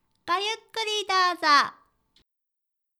ボイス
ダウンロード 女性_「ごゆっくりどうぞ」
リアクション挨拶